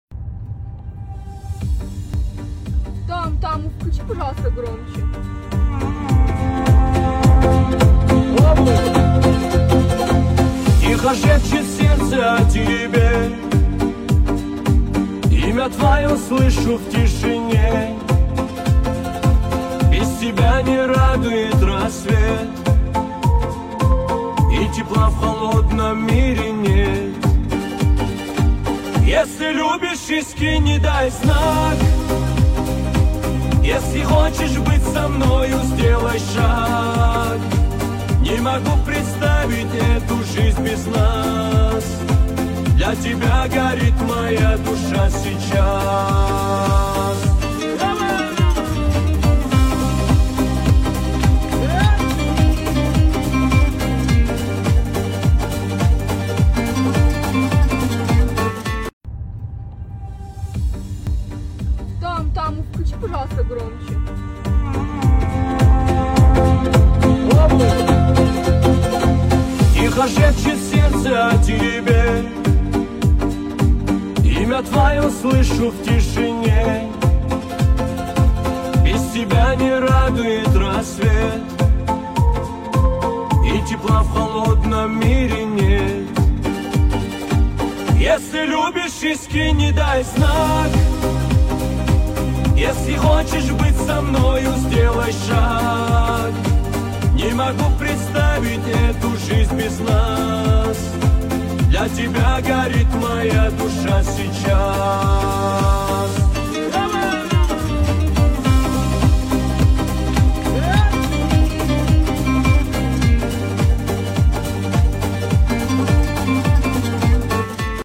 Качество: 320 kbps, stereo
Каверы 2025